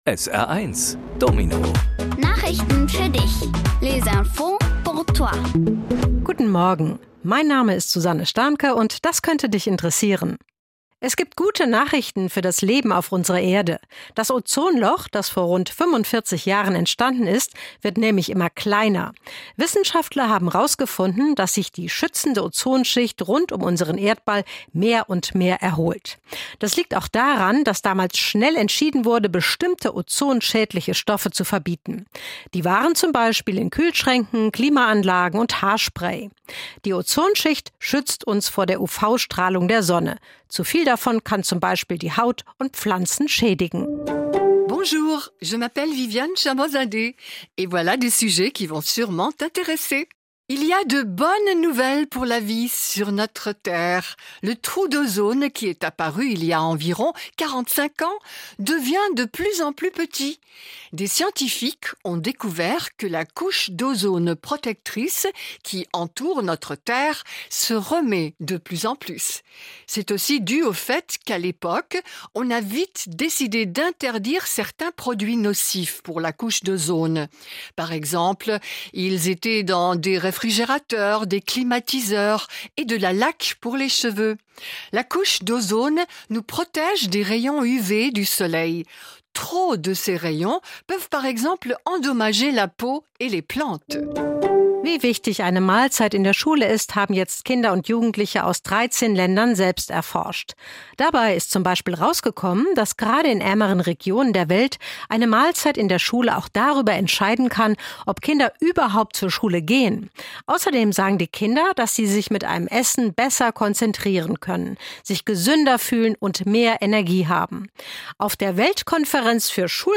Die wichtigsten Nachrichten der Woche kindgerecht aufbereitet auf Deutsch und Französisch